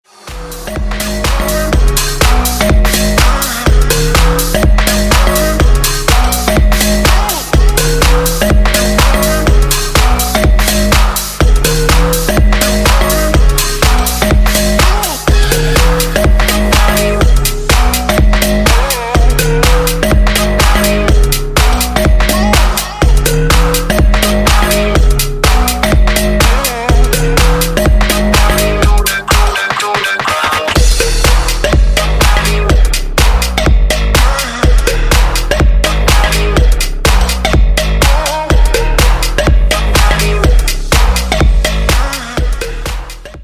• Качество: 320, Stereo
dance
Electronic
EDM
future house
Бодрый рингтон в стиле future house